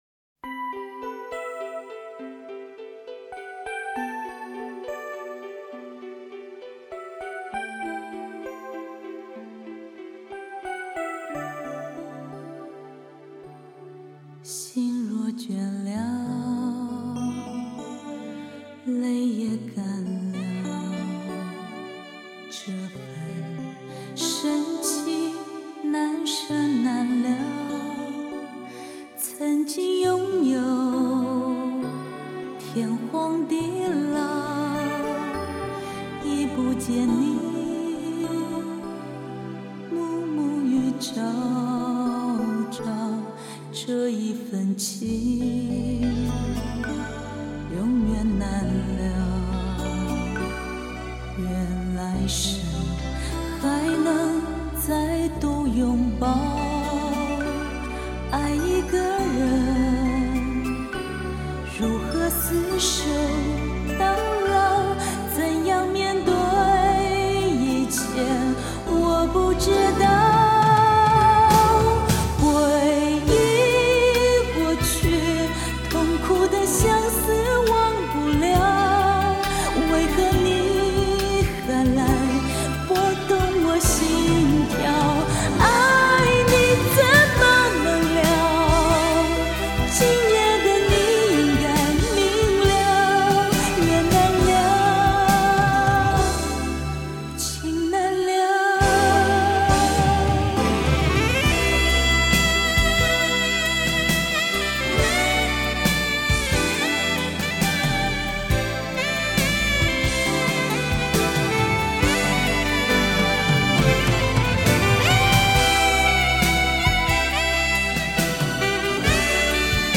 高亢、温和柔美的歌声
情歌柔软耐听